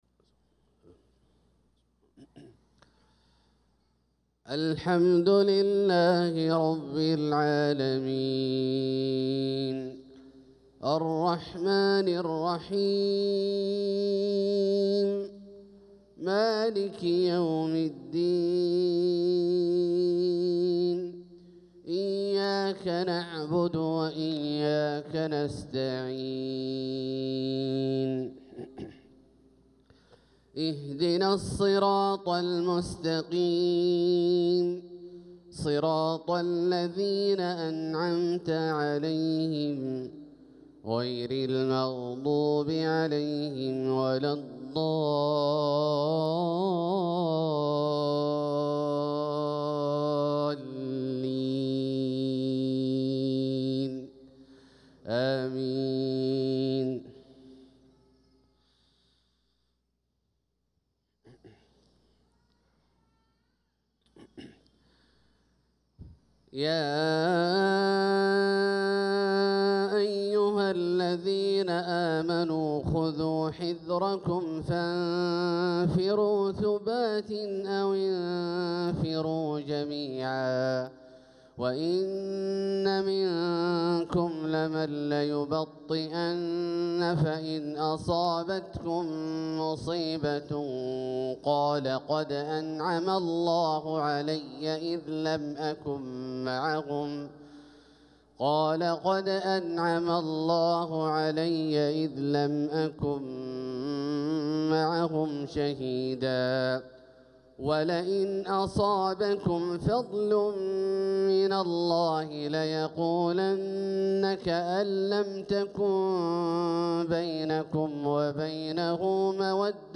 صلاة الفجر للقارئ عبدالله الجهني 11 ربيع الآخر 1446 هـ
تِلَاوَات الْحَرَمَيْن .